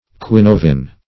Search Result for " quinovin" : The Collaborative International Dictionary of English v.0.48: Quinovin \Qui*no"vin\, n. [NL. quina nova the tree Cosmibuena magnifolia, whose bark yields quinovin.]